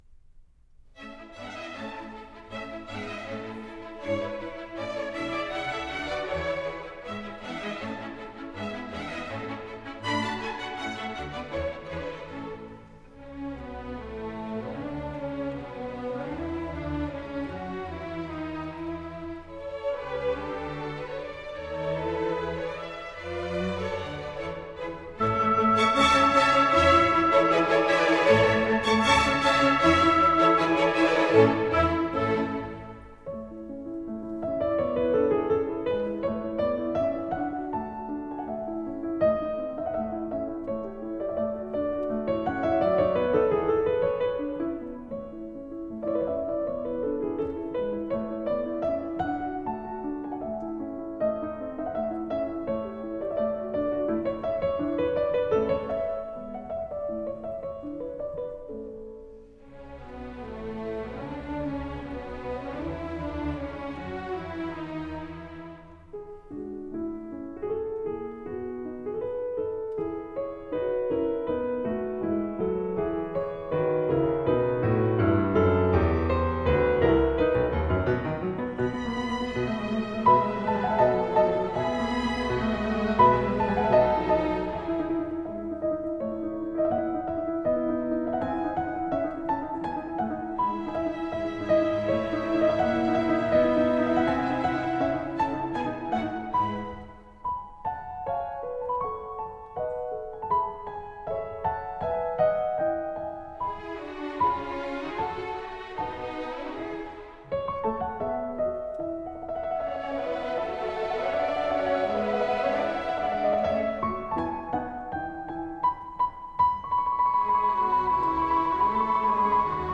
06.Piano Concerto No.12 in A-dur
Rondeau. Allegretto